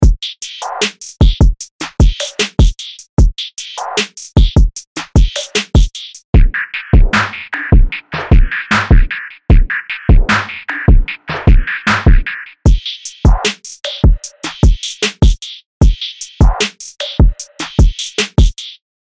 アトランタスタイルの808ベースとトラップビートが弾けるリズムサウンドを解き放つ
・力強いトラップ・ビートとチューニングされた808が、磨き上げられた荒々しさを湛える
プリセットデモ